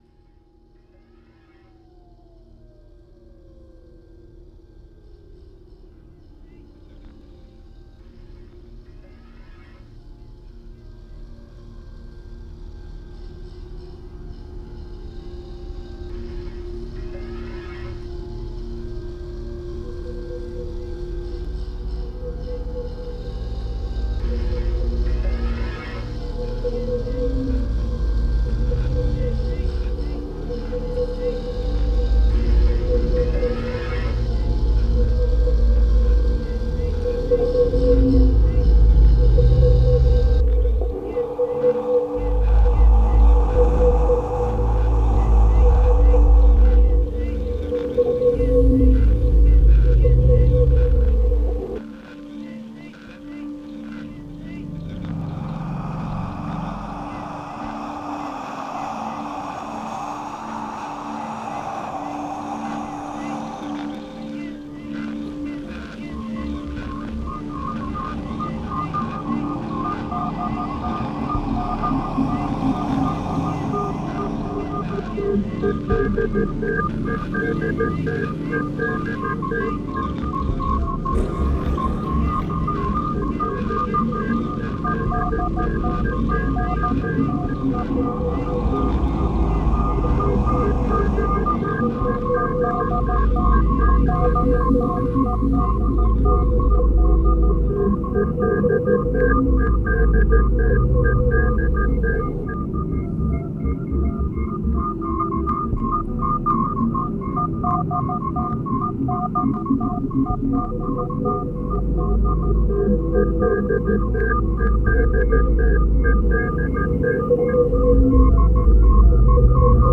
How to se balader dans les friches industrielles (instrumental)